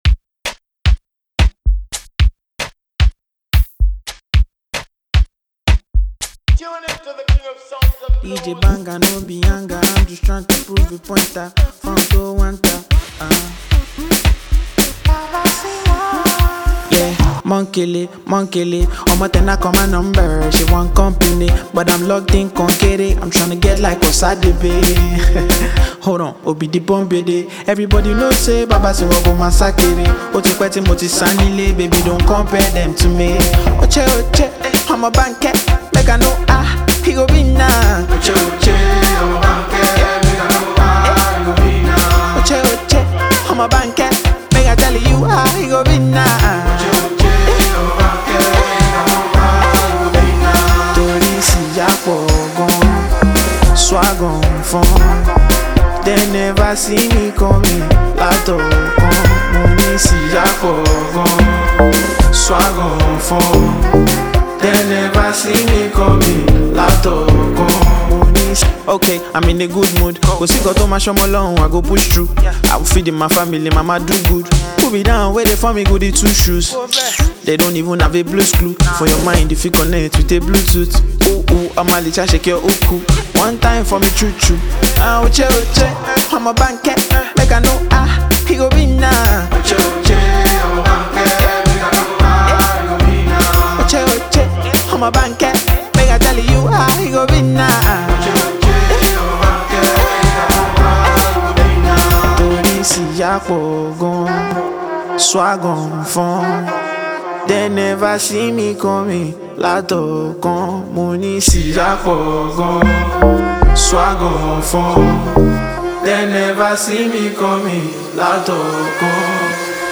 • Genre: Afrobeats